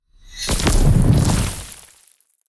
Free Frost Mage - SFX
frozen_wall_08.wav